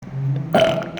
Burp As F Botón de Sonido
Sound Effects Soundboard0 views